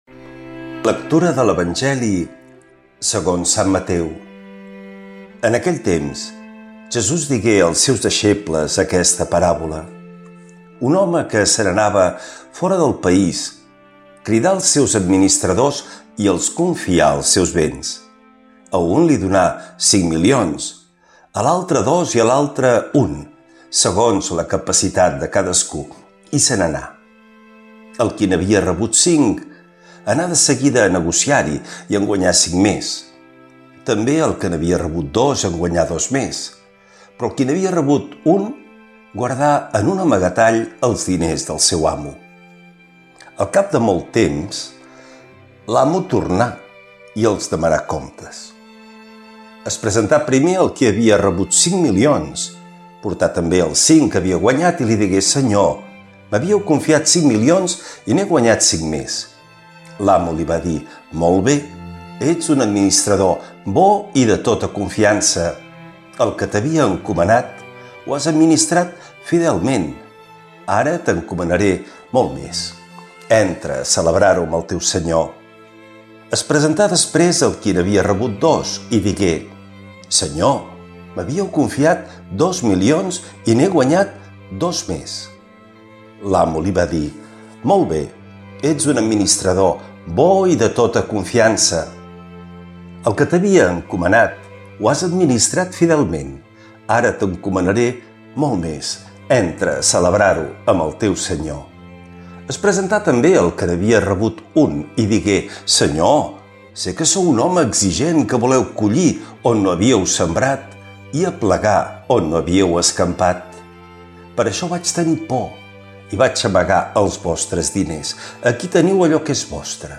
Lectura de l’evangeli segons sant Mateu